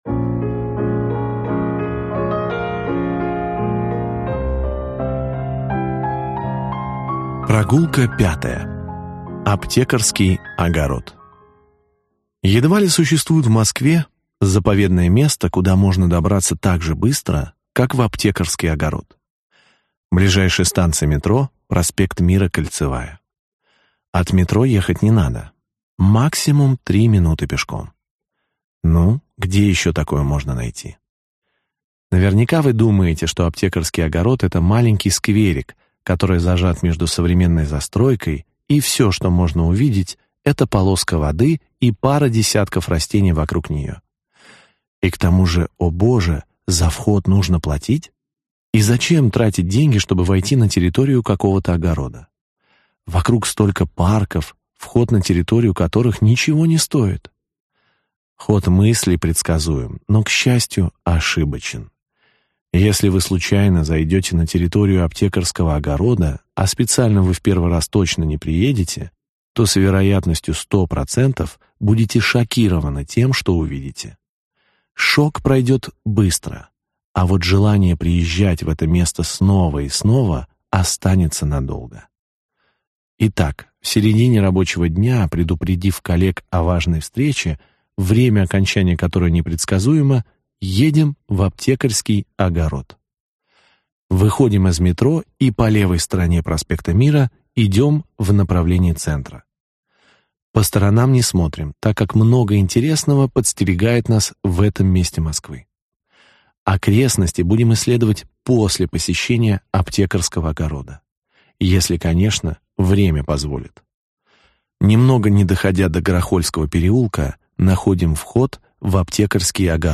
Аудиокнига 8 заповедных мест в Москве, куда можно доехать на метро. Глава 5. Аптекарский огород | Библиотека аудиокниг